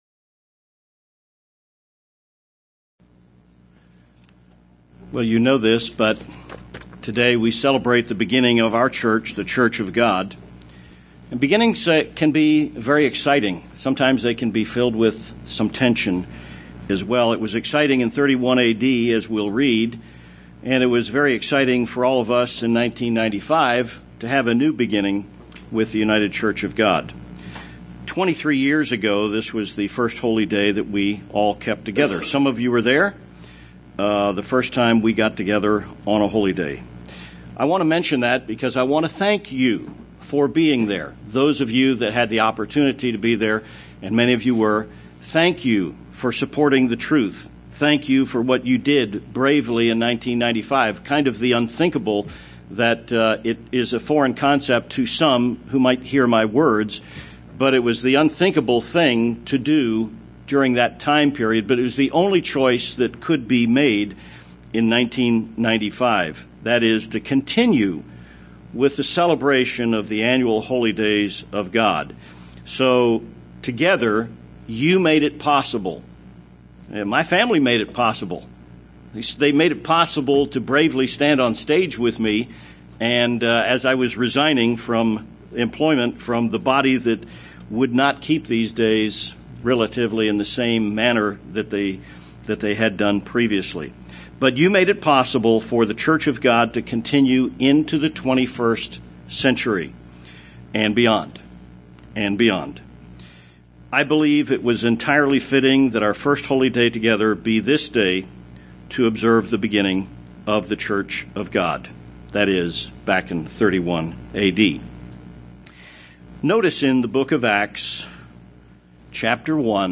Sermons
Given in El Paso, TX Tucson, AZ